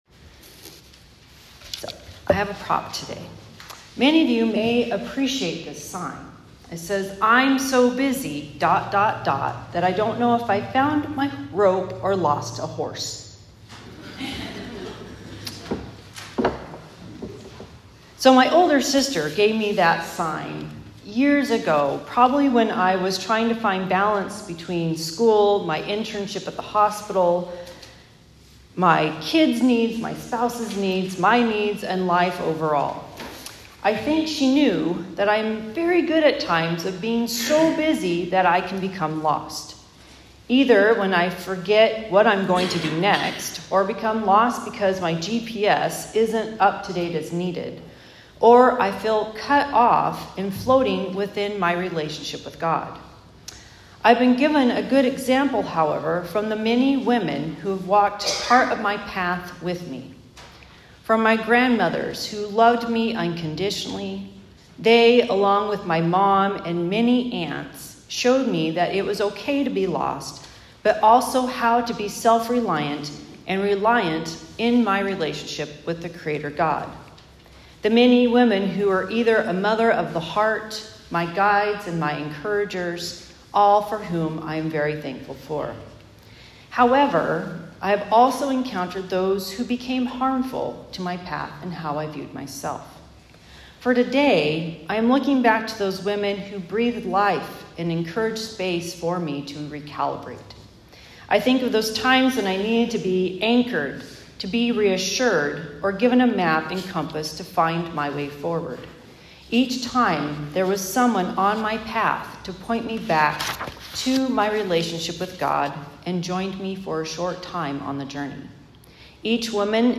Mother's Day Sermon